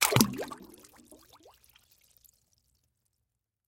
Звуки вареников
Звук утопленного в кастрюле вареника